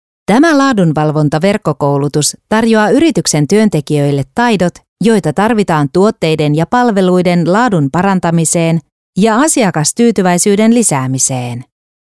E-learning
I record in a professional studio environment with professional recording equipment.
ConversationalTrustworthyEnergeticNeutralExpressive